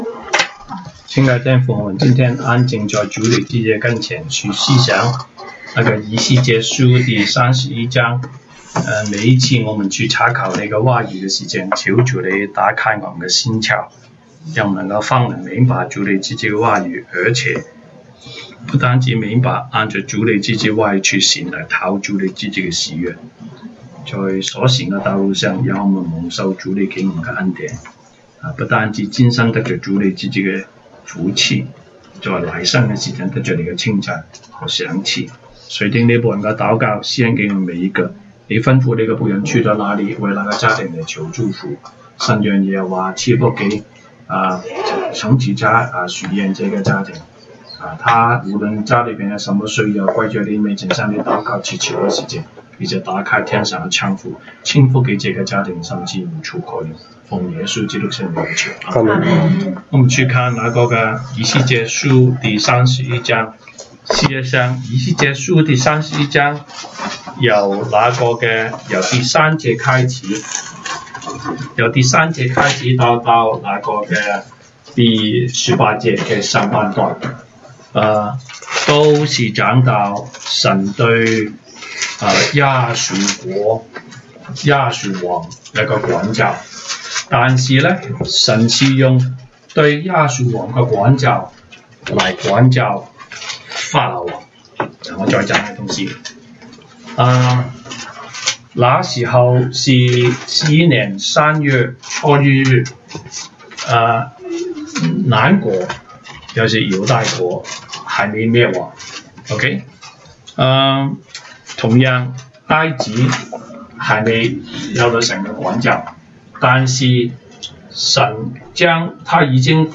週一國語研經 Monday Bible Study « 西堂證道